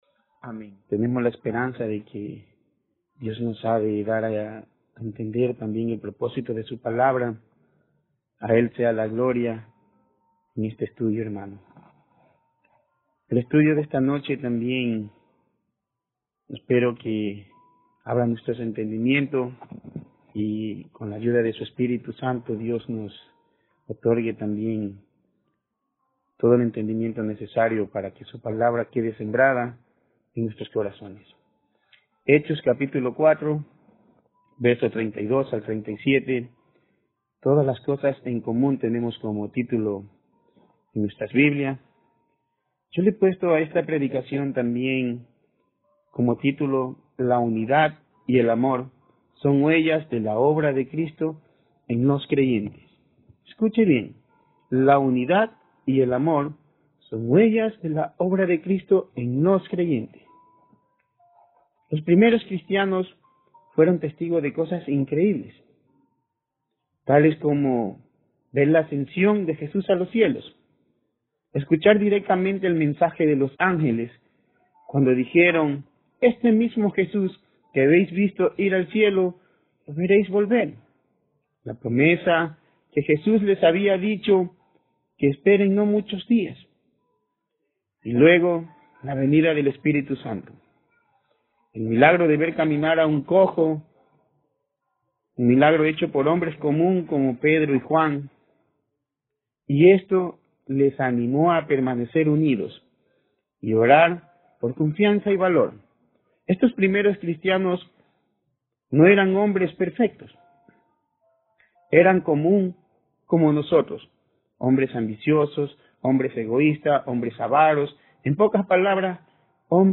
Hechos 4:32-37 Tipo: Sermón Bible Text